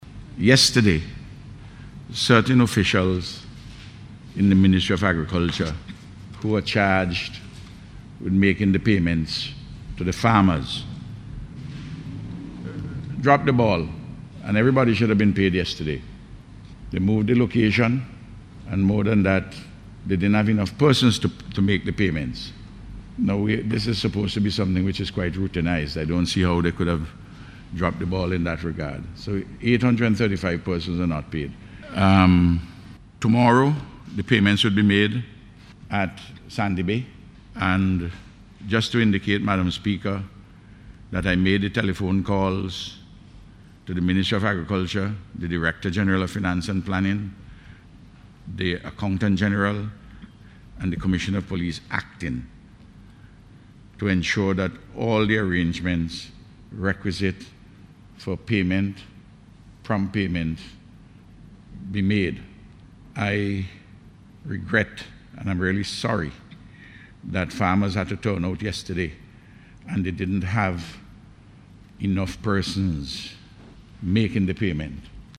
The Prime Minister addressed the matter in Parliament this morning.